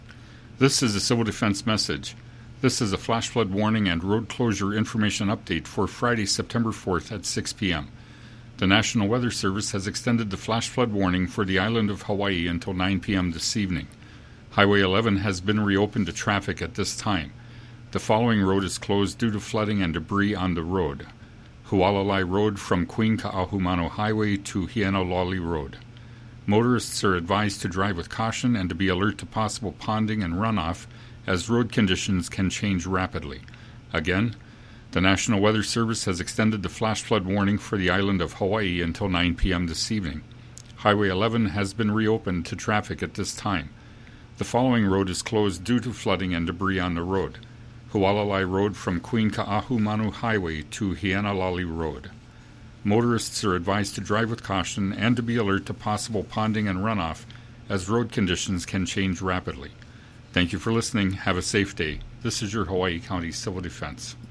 Hawaii County Civil Defense 6 p.m. update on the flash flood conditions